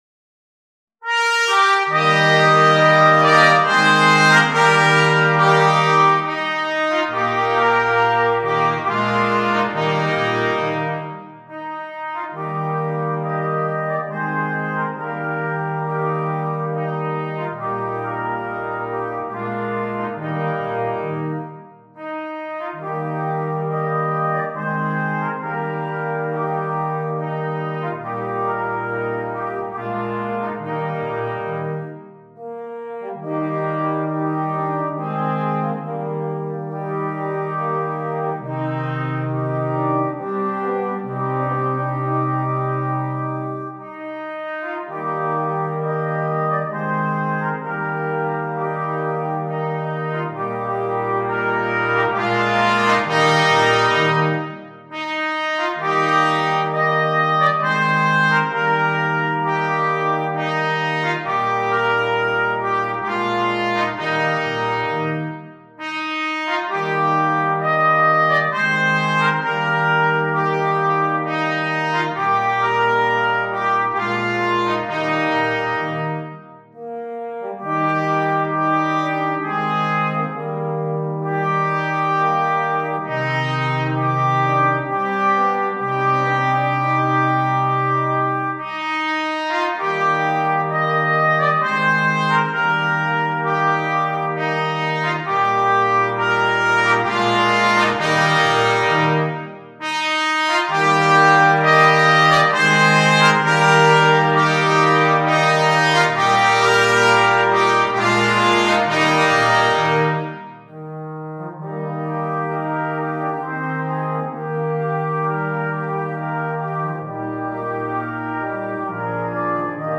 Entertainment
Part 1: Bb Trumpet, Bb Cornet
Part 3: F Horn
Part 4: Trombone – Bass clef
Part 5: Tuba